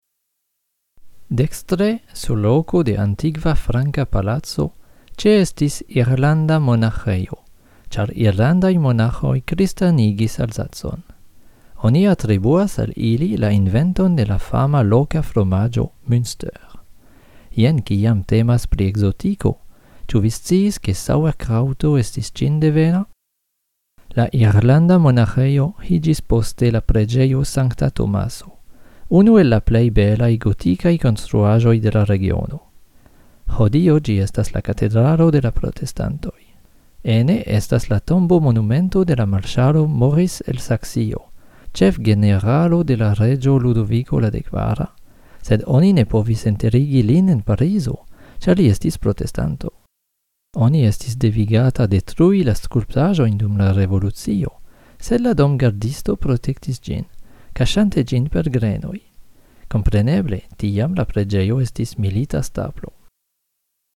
Individuaj sonregistraĵoj (Vira voĉo vidu dekstre, virina vidu maldekstre)